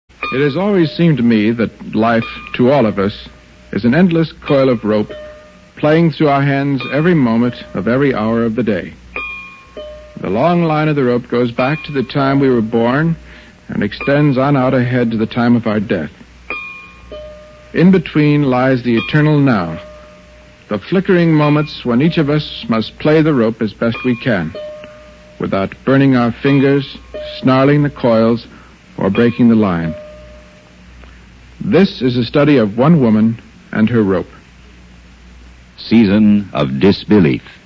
First broadcast on CBS Radio, Feb 17, 1956
He wrote and read a short introduction to each one, using the metaphor of life as a coiled rope.
here to hear Bradbury's introduction to "Season of Disbelief".